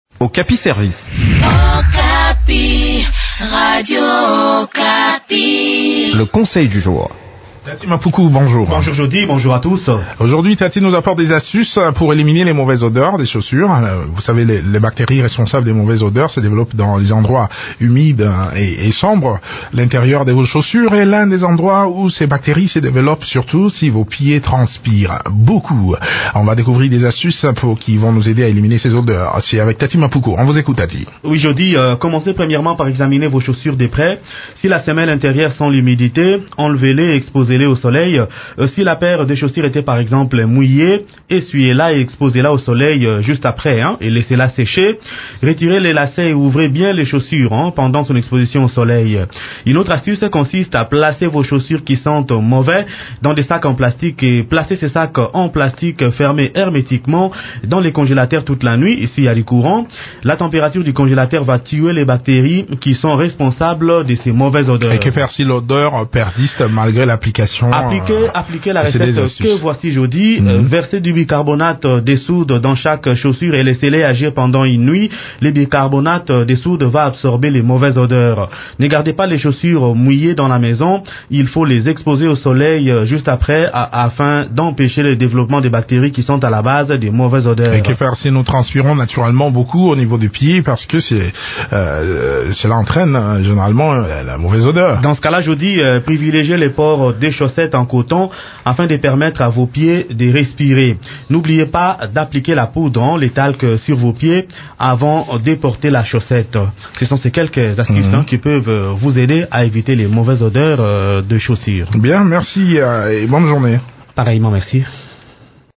Découvrez des astuces qui peuvent vous aider à éliminer ces odeurs dans cette chronique